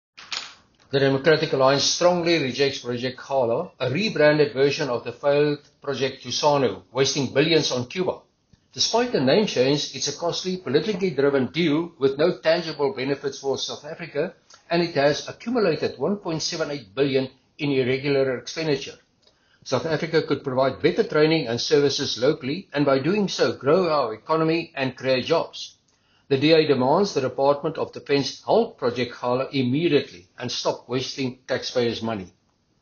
Afrikaans soundbites by Chris Hattingh MP.